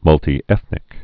(mŭltē-ĕthnĭk, -tī-)